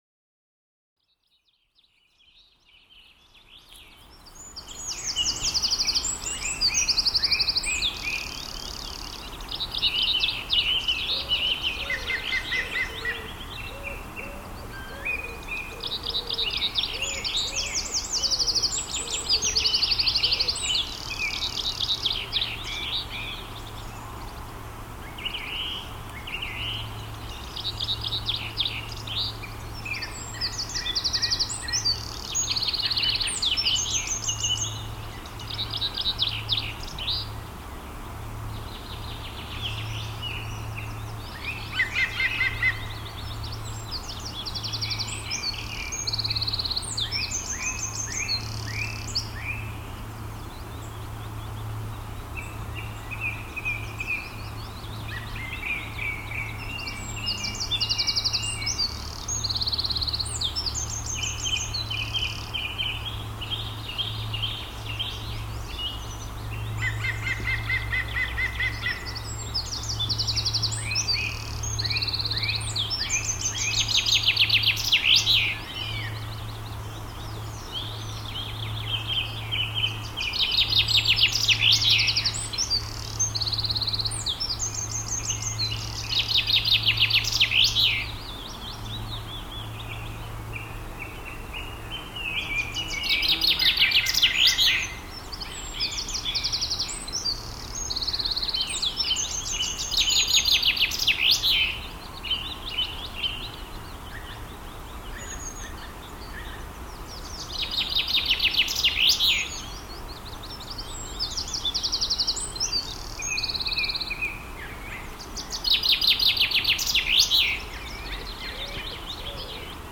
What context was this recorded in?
This recording was made in a garden in Highland Perthshire, in the morning, in May, using once again the Soundman OKM II with the Sony portable DAT recorder TCD-D7, the SBM-1 (Super Bit Mapping) from Sony and a Sennheiser Dummy Head (Kunstkopf) to hold the microphones. OKM Garden.MP3